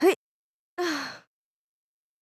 casting_fail.wav